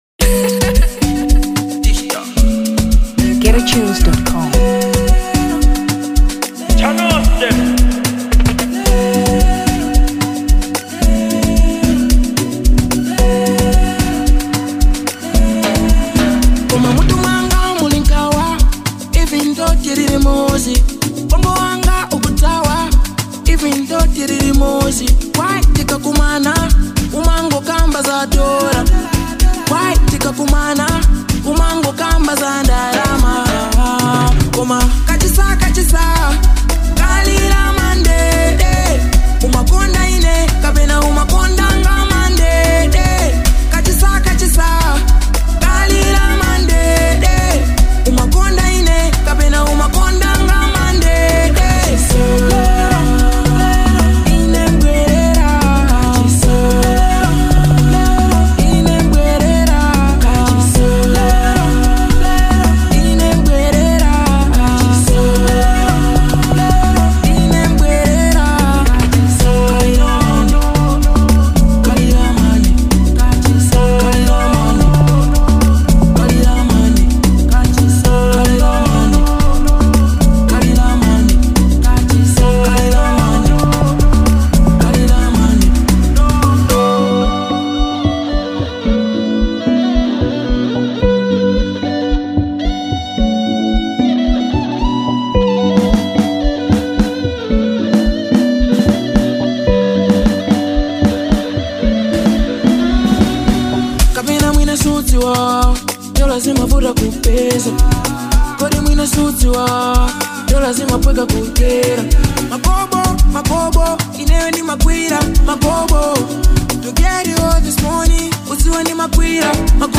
Amapiano 2023 Malawi